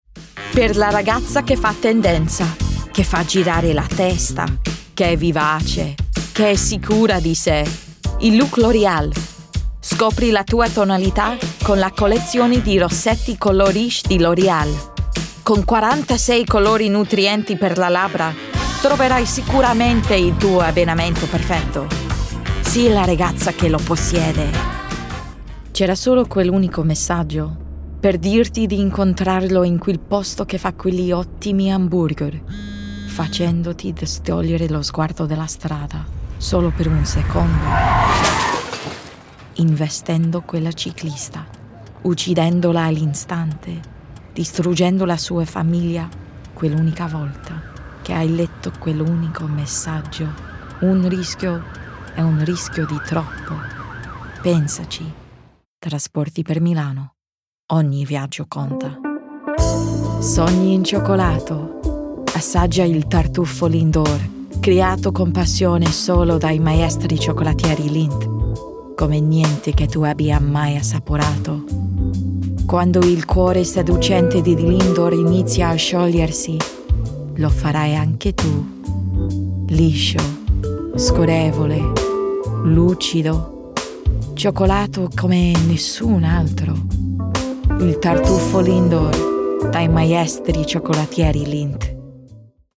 Cool, Young, Conversational & Warm
Friendly/Approachable
Light/Fresh/Bright
Warm/Reassuring/Trustworthy
Commercial Reel
Commercial, Bright, Upbeat, Italian